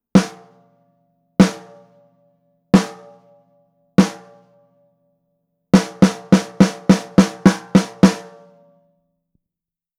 実際の録り音
スネア
57ドラムスネア-.wav